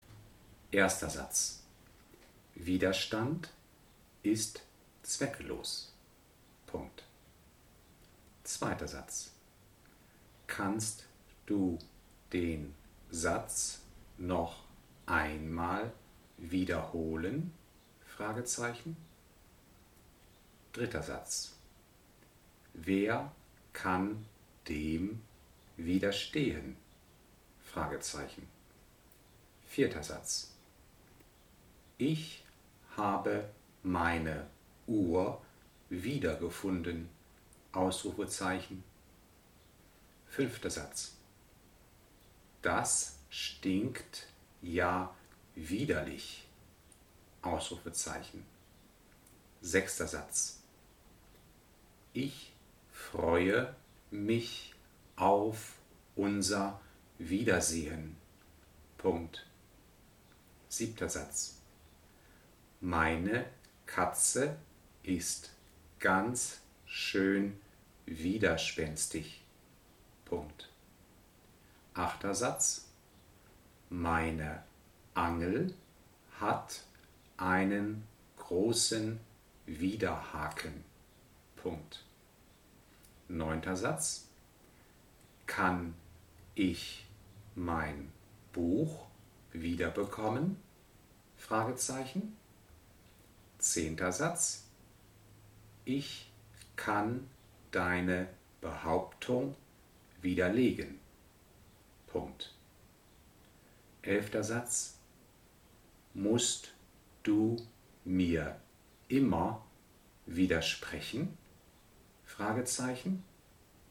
Diktate als MP3
Darin sind die Übungssätze Wort für Wort mit kleinen Pausen diktiert.